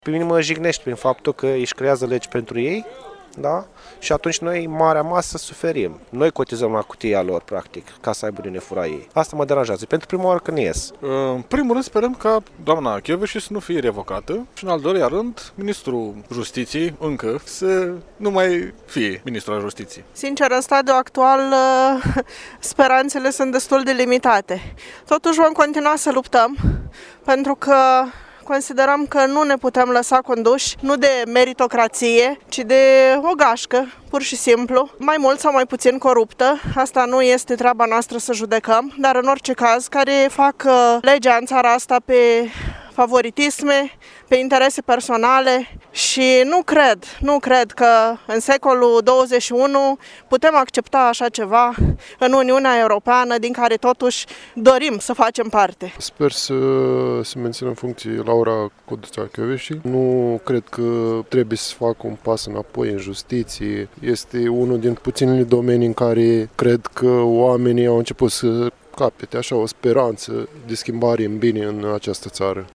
La Iași, protestează aproximativ 300 de oameni. Aceștia s-au adunat în Piața Unirii și apoi, au plecat către Prefectură.
25-feb-ora-20-vox-Piata-Unirii.mp3